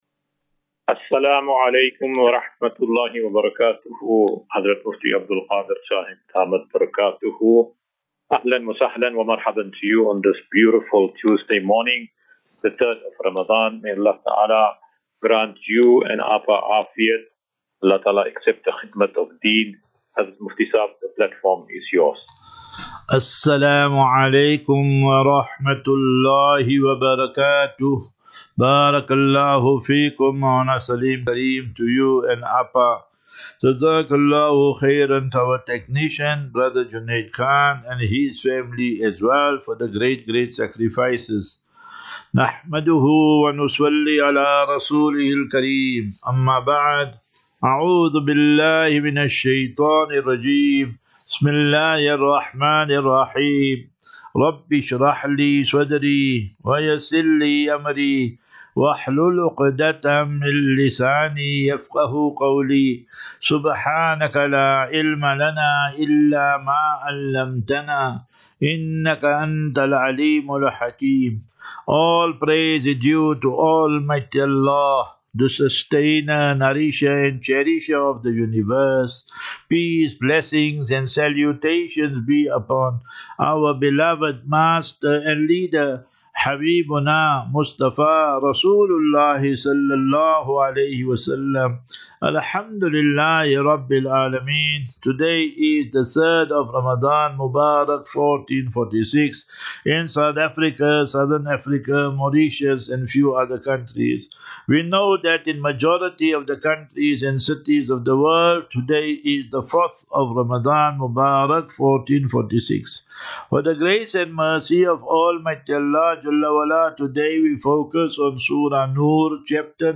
As Safinatu Ilal Jannah Naseeha and Q and A 4 Mar 04 March 2025.